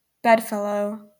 Ääntäminen
US GA : IPA : /ˈbɛdˌfɛloʊ/